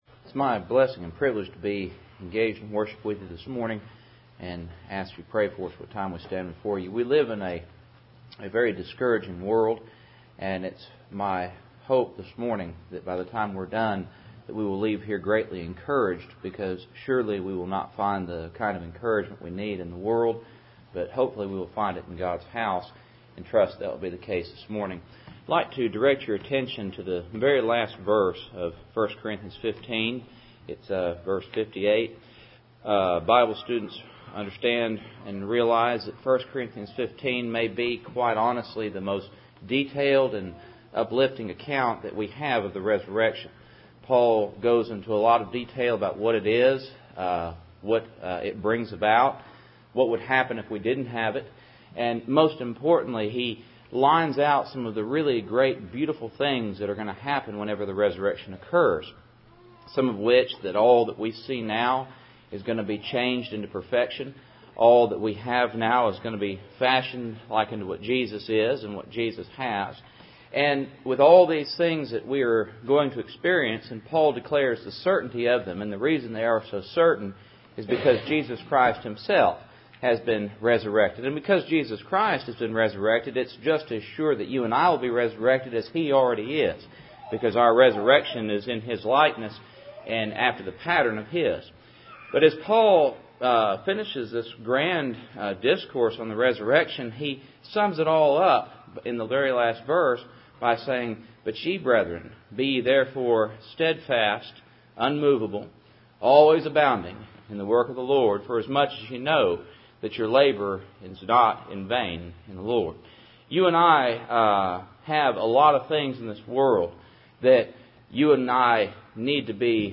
1 Corinthians 15:58 Service Type: Cool Springs PBC 1st Saturday %todo_render% « Part 2